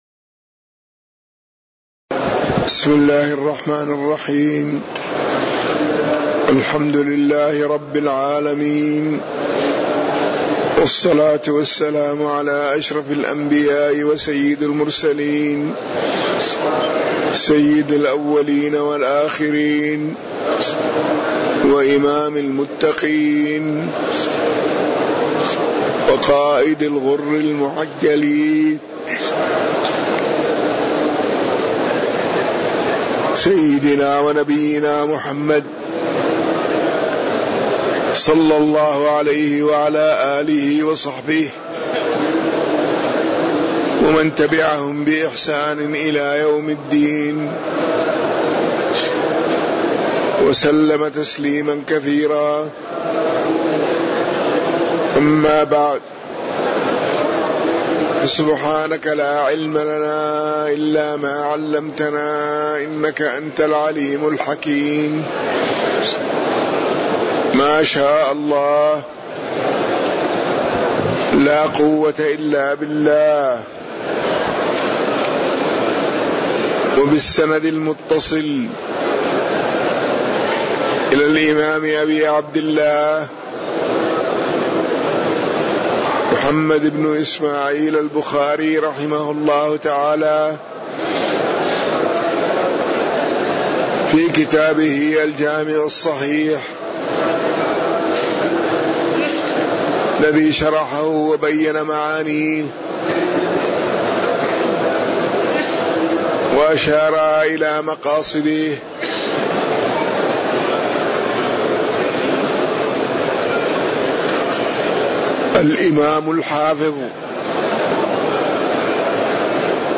تاريخ النشر ١١ رجب ١٤٣٩ هـ المكان: المسجد النبوي الشيخ